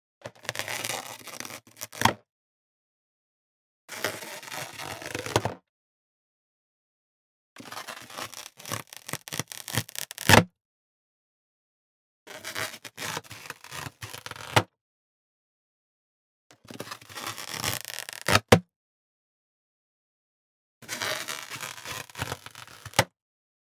Balloon Inflated Handling Sound
cartoon
Balloon Inflated Handling